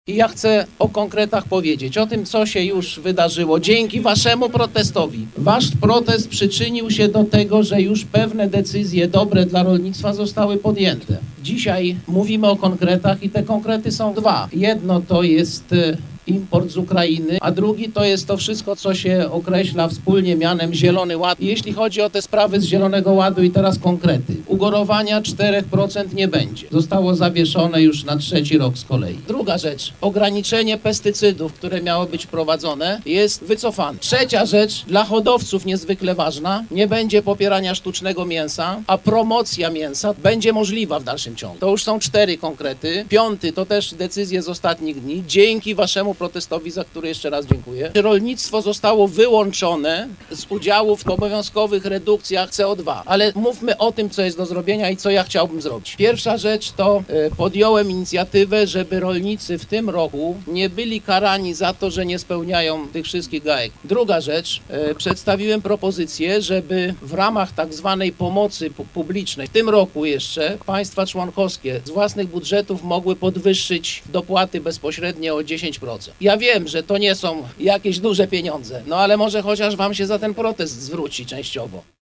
Komisarz Unii Europejskiej ds. rolnictwa Janusz Wojciechowski spotkał się dziś z protestującymi rolnikami województwa zachodniopomorskiego. Do spotkania doszło na węźle Myślibórz w pobliżu Renic po godzinie 15.